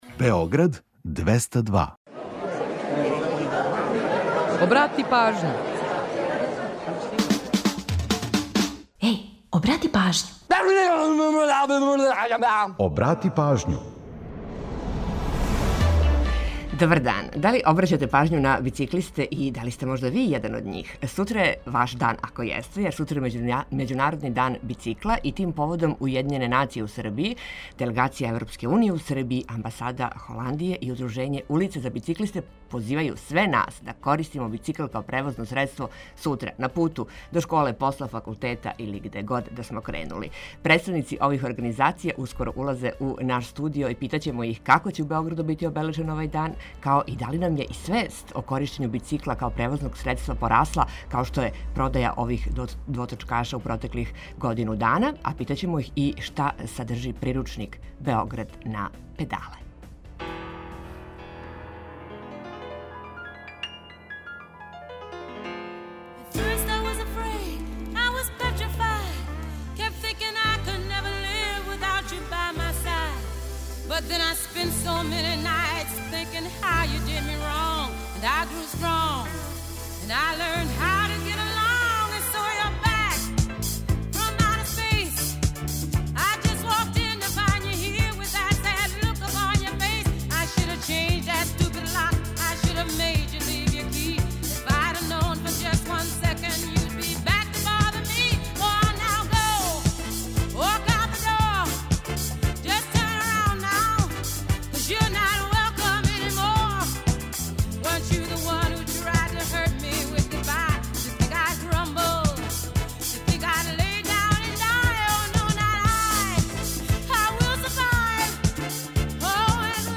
Представници ових организација су наши данашњи гости и питаћемо их како ће у Београду бити обележен Међународни дан бицикла, као и да ли нам је и свест о коришћењу бицикла као превозног средства порасла, као што је продаја ових двоточкаша у протеклих годину дана, али и шта садржи приручник „Београд на педале”.
Ту је и пола сата резервисаних за домаћицу, музику из Србије и региона, прича о једној песми и низ актуелних занимљивости и важних информација.